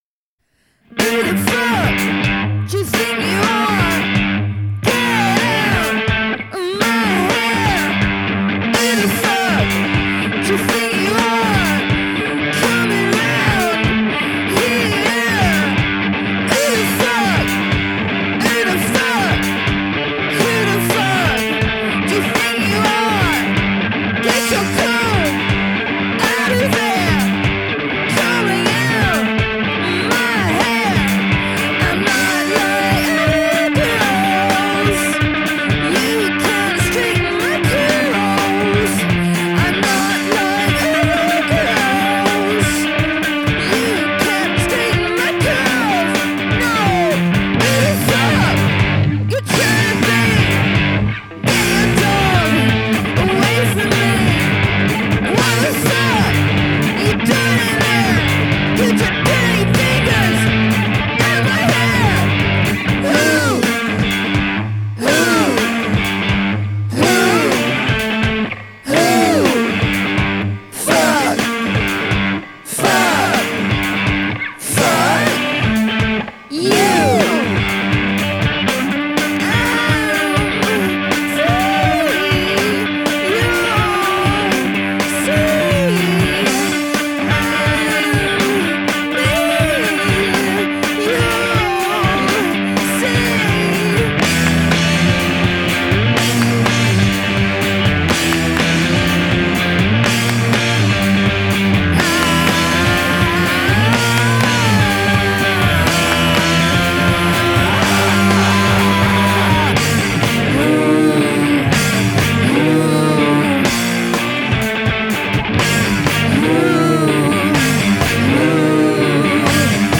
It’s a sloppy, stroppy, brilliantly sweary track.